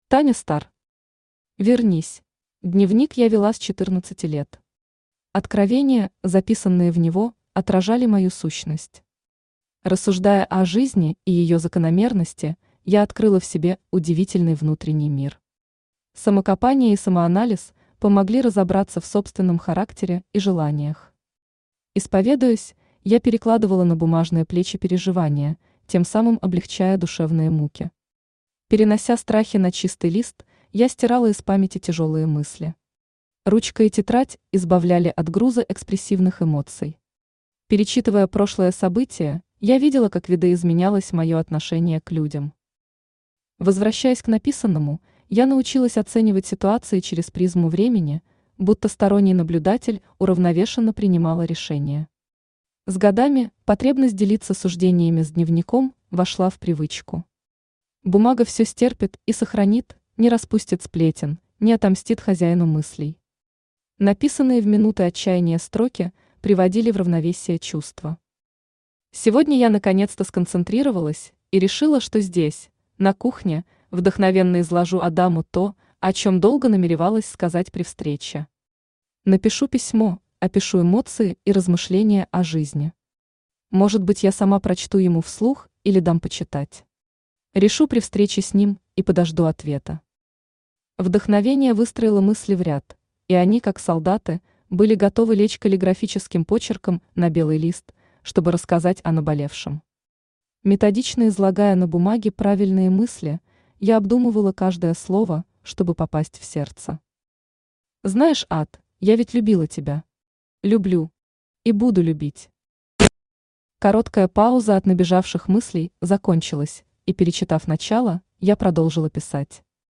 Аудиокнига Вернись!
Читает аудиокнигу Авточтец ЛитРес.